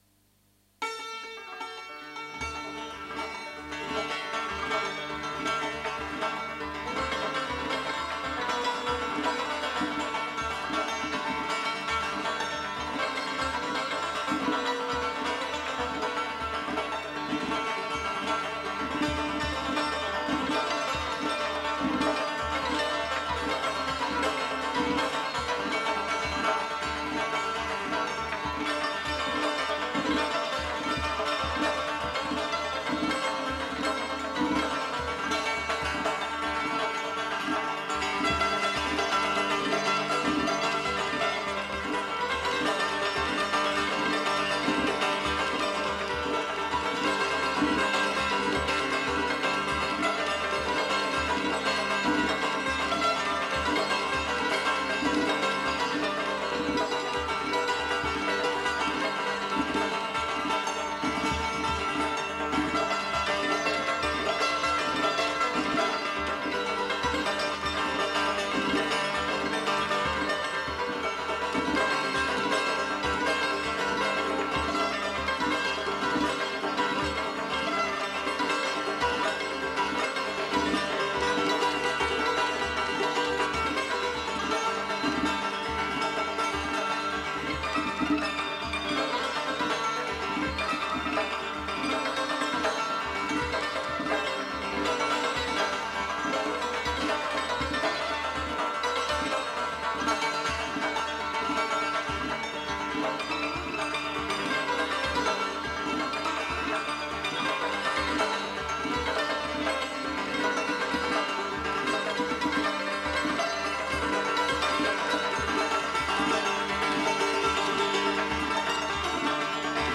chehaar mezraab